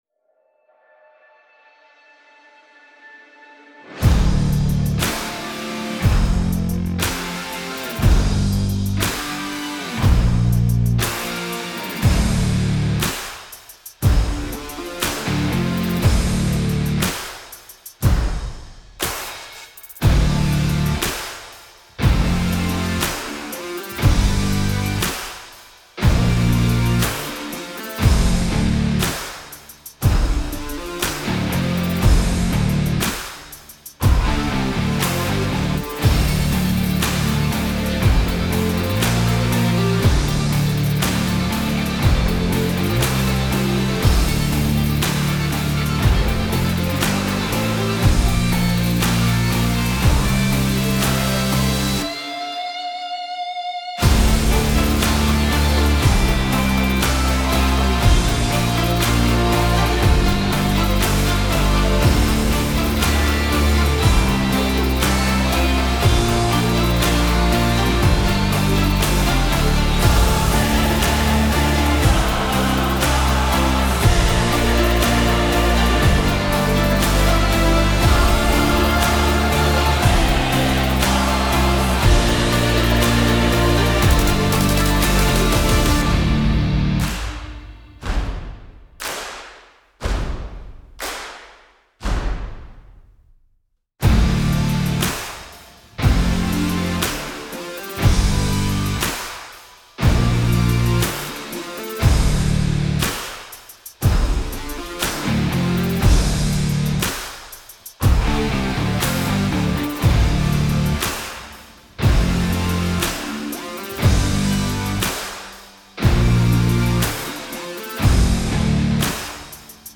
Trailer Music
موسیقی بی کلام حماسی موسیقی بی کلام راک